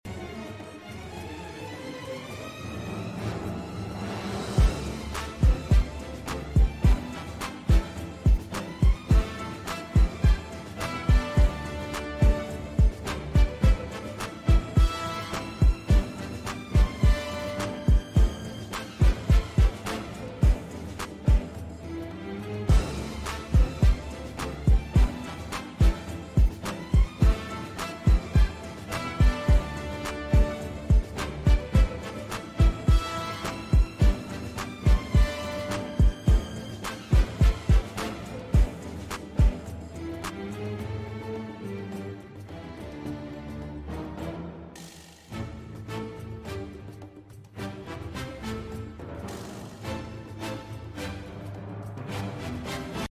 Trap Beat Remix